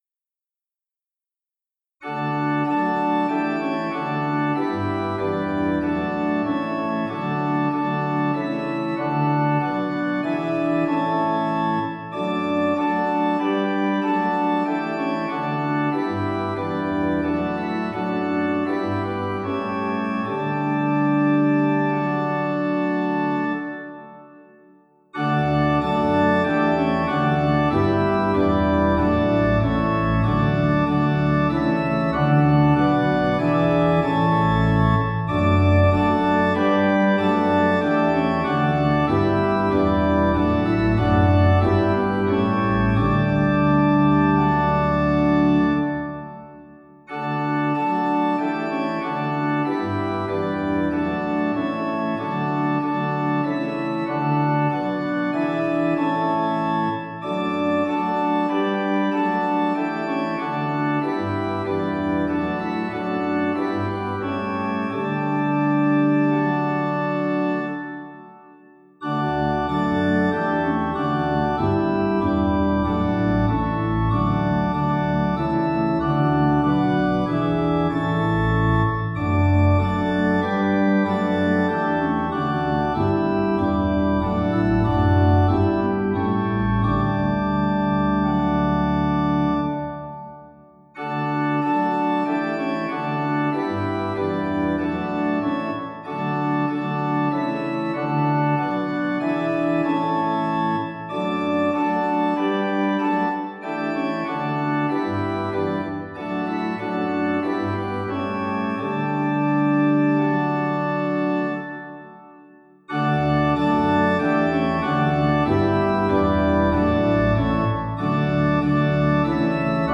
Extract Only